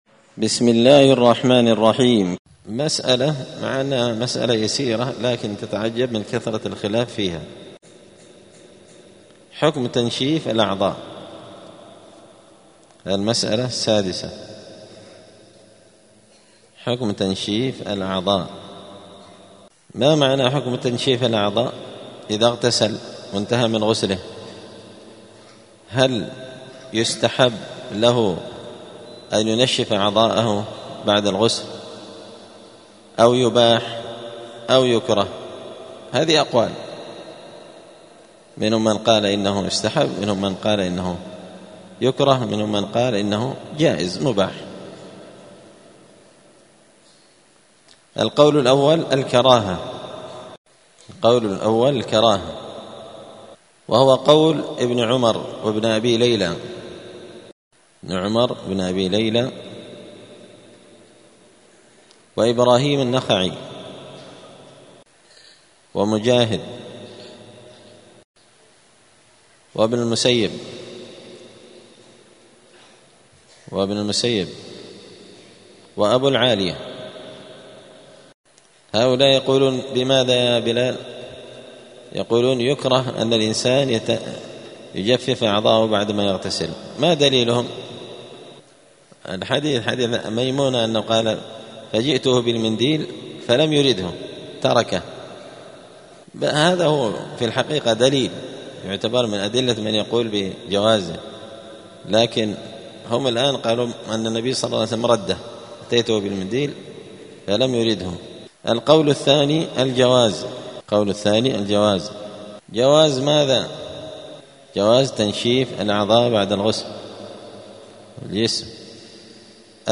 دار الحديث السلفية بمسجد الفرقان قشن المهرة اليمن
*الدرس الثالث والثمانون [83] {باب صفة الغسل حكم تنشيف الأعضاء}*